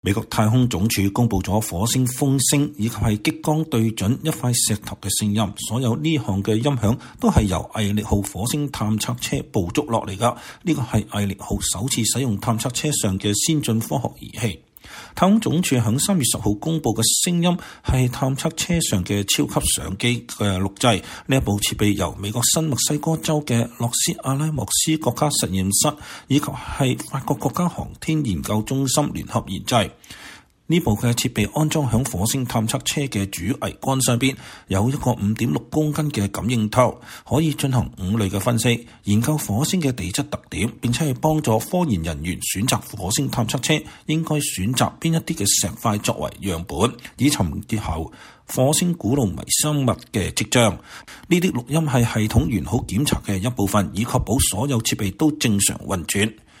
火星首傳激光擊石聲：美國太空總署公佈“毅力號”錄音
美國太空總署(NASA)公佈了火星風聲和激光對準一塊石頭的聲音，所有這些音響都是由“毅力號”( Perseverance)火星探測車捕捉下來的，這是“毅力號”首次使用探測車上的先進科學儀器設備。